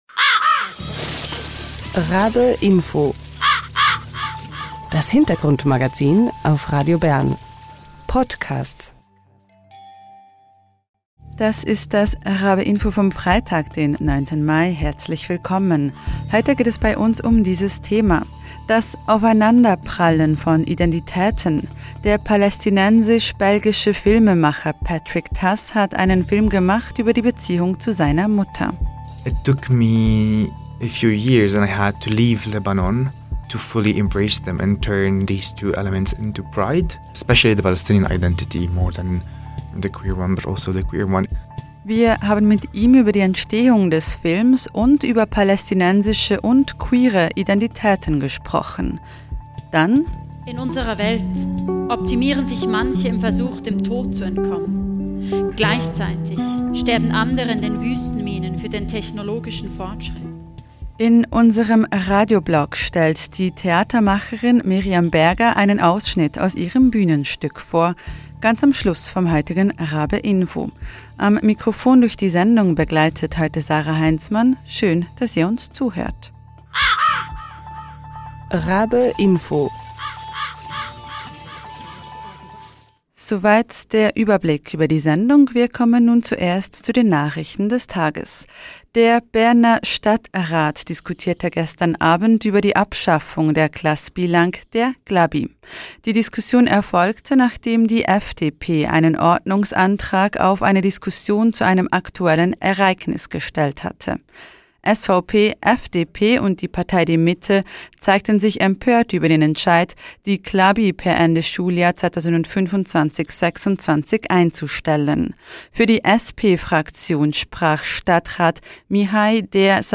Queere palästinensische Identität: Talk